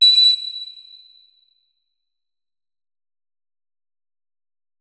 46265b6fcc Divergent / mods / Hideout Furniture / gamedata / sounds / interface / keyboard / flute / notes-83.ogg 40 KiB (Stored with Git LFS) Raw History